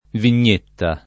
[ vin’n’ % tta ]